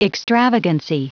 Prononciation du mot extravagancy en anglais (fichier audio)
Prononciation du mot : extravagancy